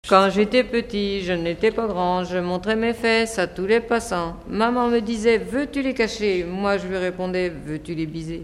Genre brève
Pièce musicale inédite